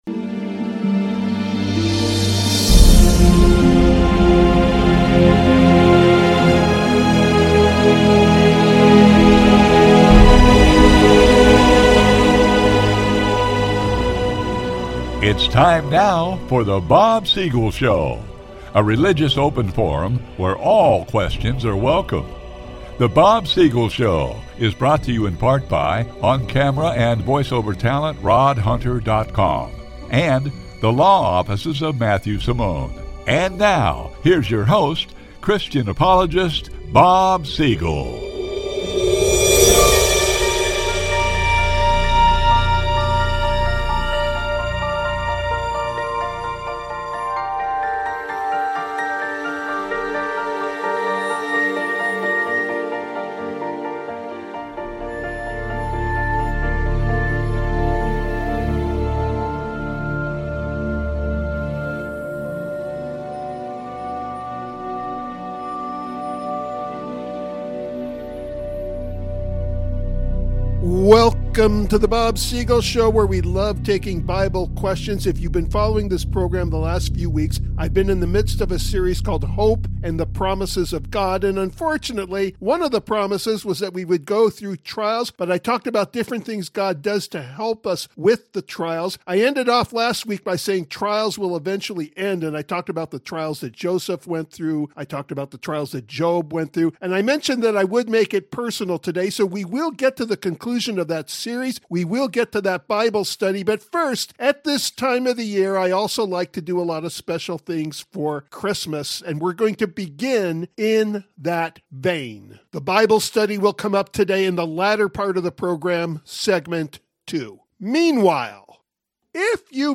Author Audio Reading